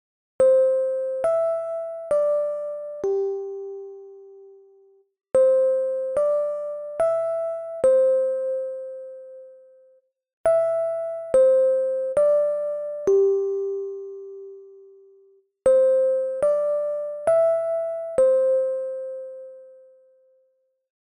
알림음 8_SchoolChime2.mp3